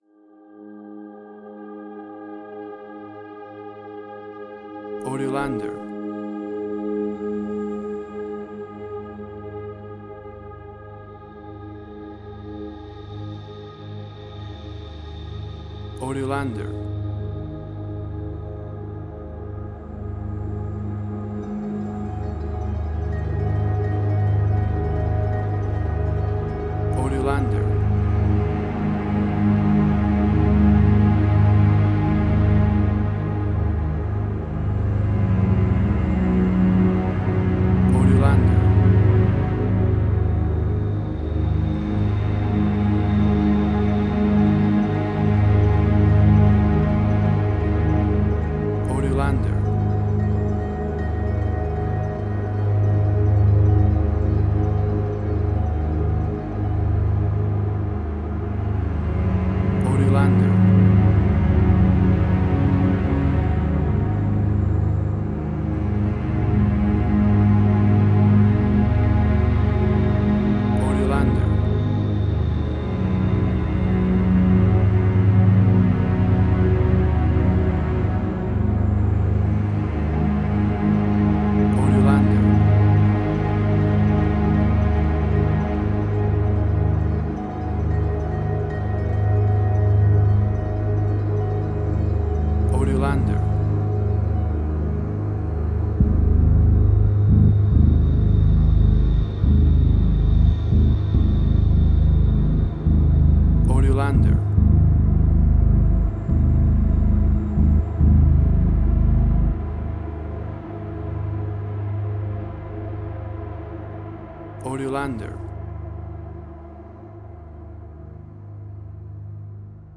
Post-Electronic.